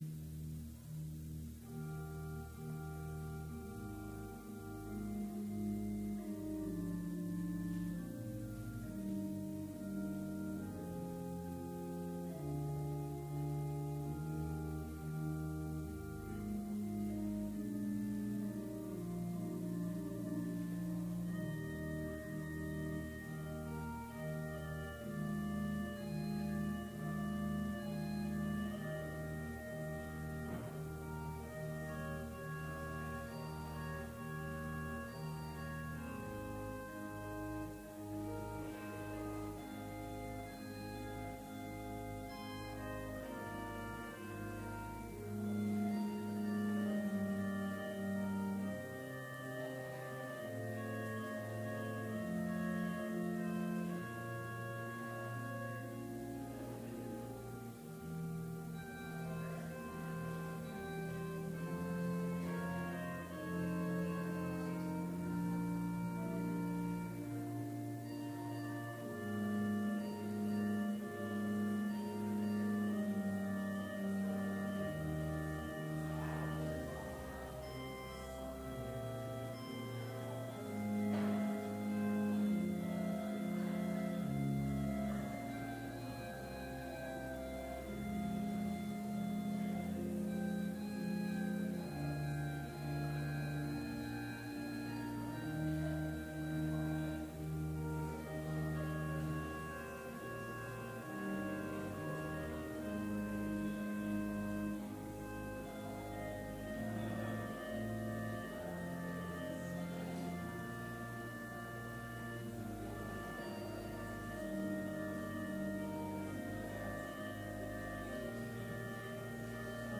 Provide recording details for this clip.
Complete service audio for Chapel - April 2, 2019